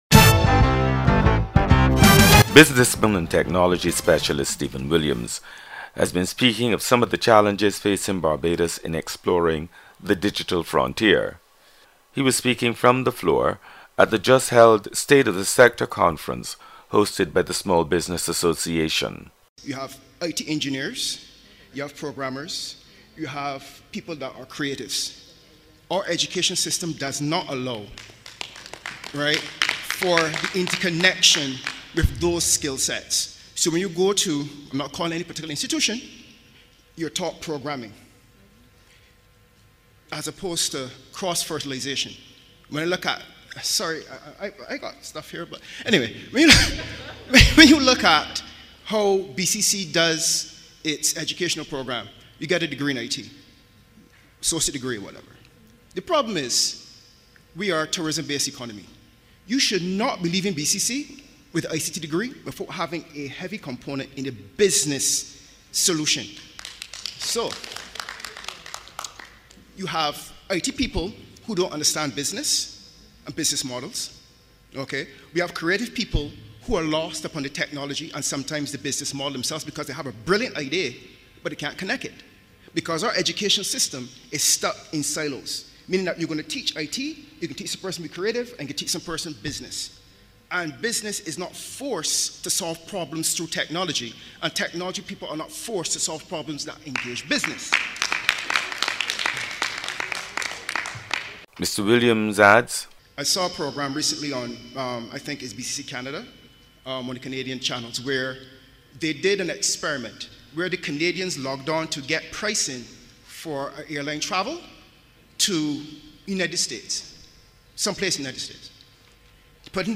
He was speaking from the floor at the just held state of the Sector conference hosted by the Small Business Association.